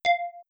ping.wav